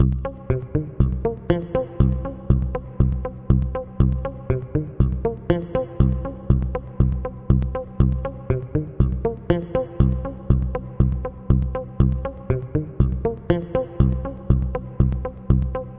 描述：电子低音回路（120 bpm）
Tag: 电子 合成器 延迟 跳舞 低音